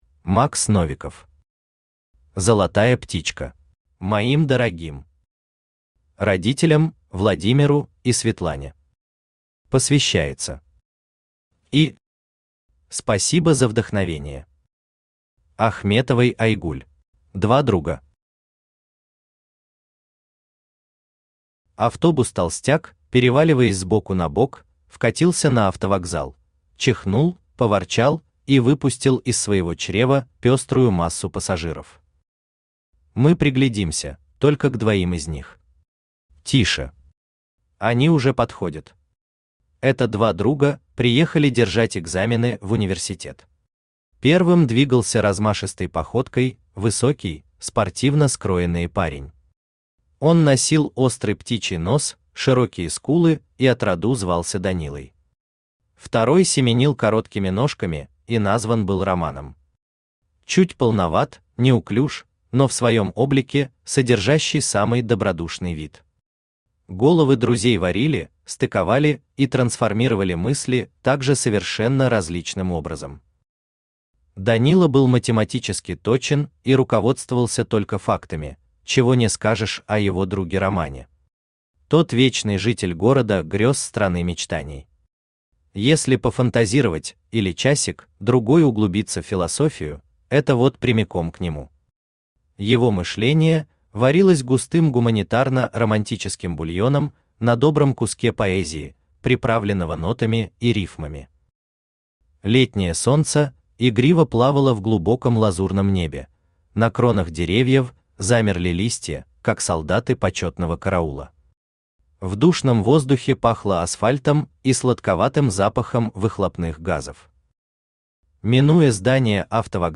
Аудиокнига Золотая птичка | Библиотека аудиокниг
Aудиокнига Золотая птичка Автор Макс Новиков Читает аудиокнигу Авточтец ЛитРес.